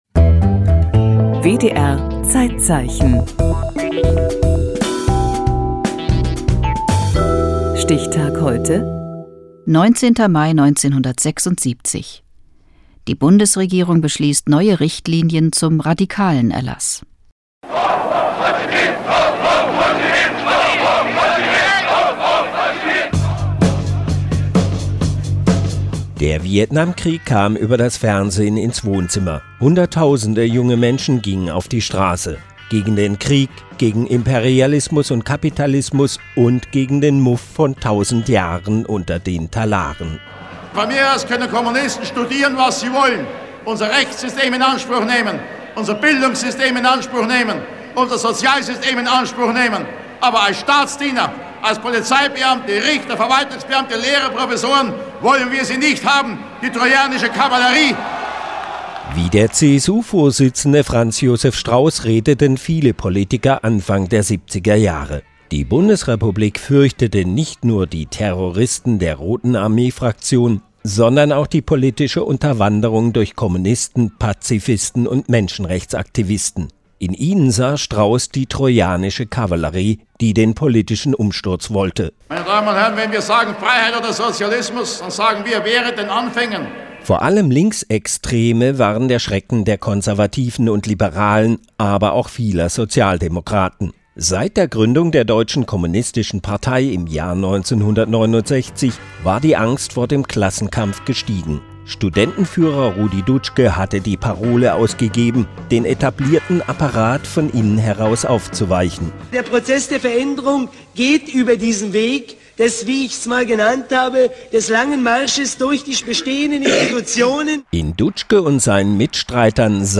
Der Rundfunkkanal WDR5 nahm in seiner Reihe „ZeitZeichen“ den 40. Jahrestag neuer NRW-„Richtlinien“ zum „Radikalenerlass“ am 19.05.1976 zum Anlass, um umfassend über die Berufsverbote und die Schnüffeleien des „Verfassungsschutzes“ zu berichten. Besonderes Gewicht legte die Sendung darauf, dass die Berufsverbote gegen Kommunisten und Pazifisten von ehemalige Nazi-Aktivisten forciert wurden.